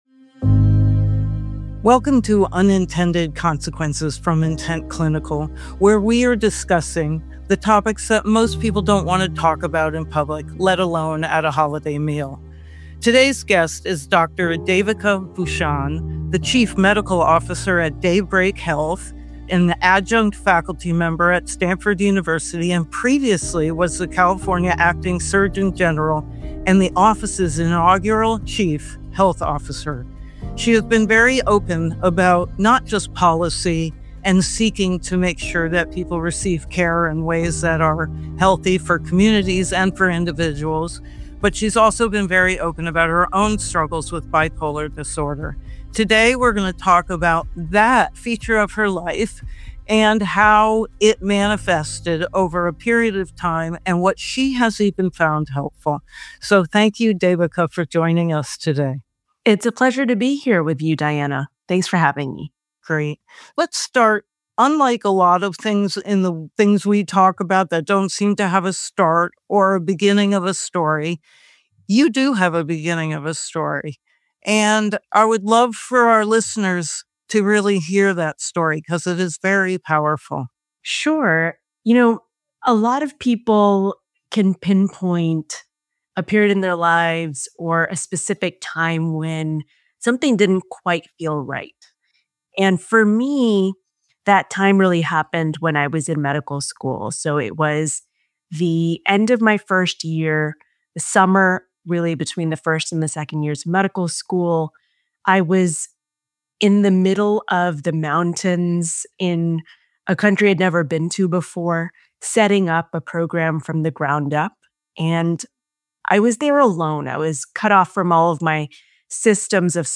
Unintended Consequences, a podcast from Intent Clinical, discusses topics that no one wants to bring up in public. Through a series of interviews with individuals and experienced professionals, Unintended Consequences will cover stories of success, wealth, drugs, addiction, disease, depression, aging, dementia, infertility, recovery, healing, optimizing, and thriving.